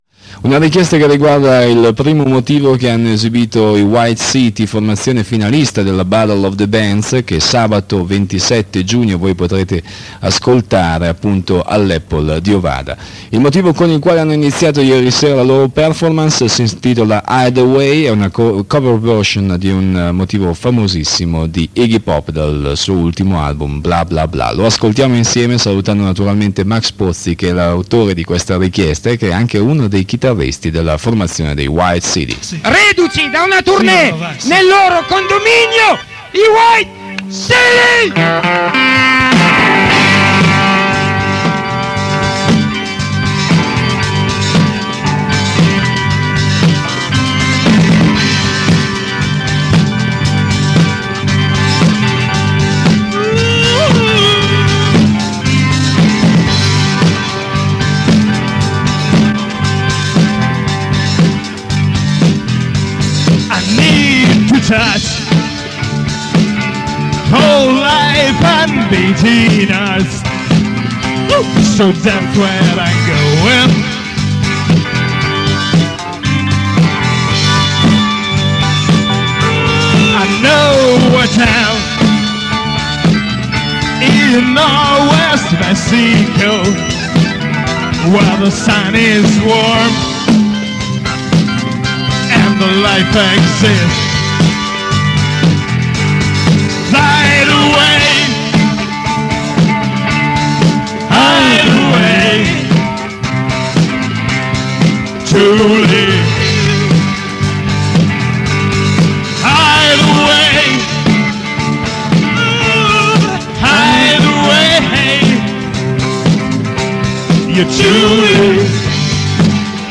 ESTRATTI DAL CONCERTO
chitarra solista
chitarra ritmica
tastiere
basso
batteria